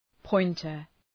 Προφορά
{‘pɔıntər}